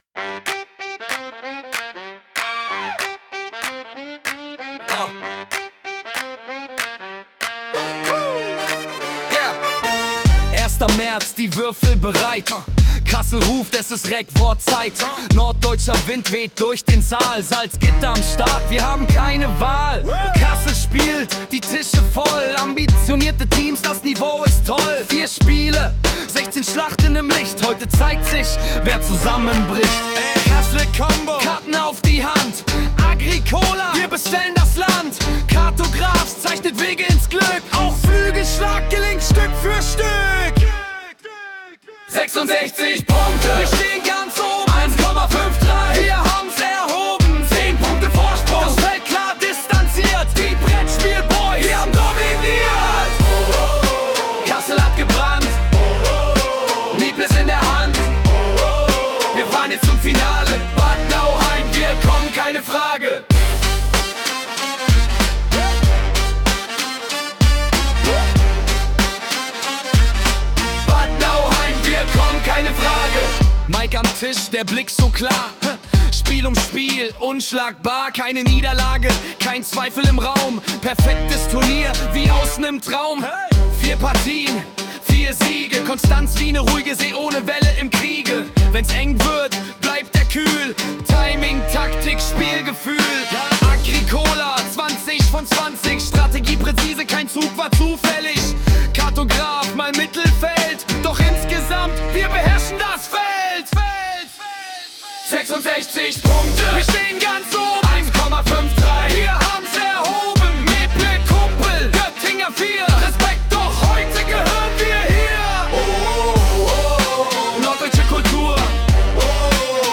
Wir haben unser Ergebnis mittels SunoAI vertont: